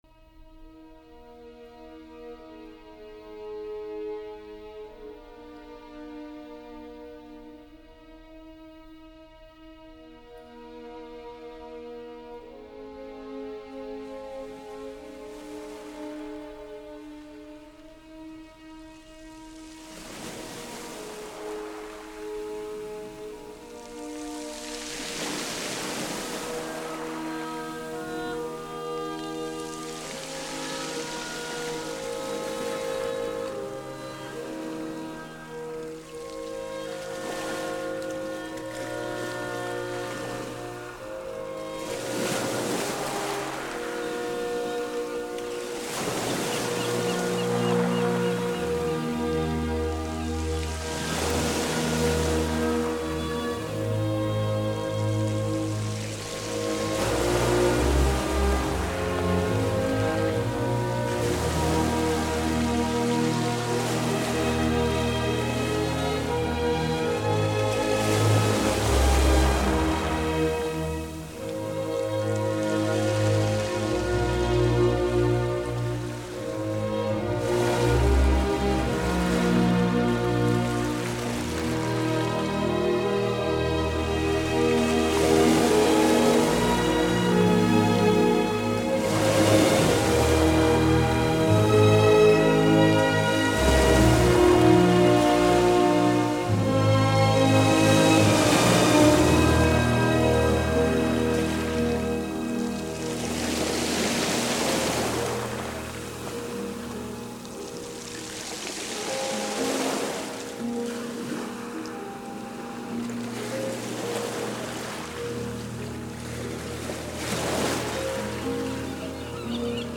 bilateral sound tracks